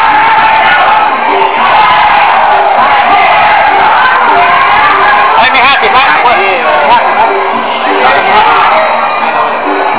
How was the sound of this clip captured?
Muay Thai kickboxing at the National Stadium in Bangkok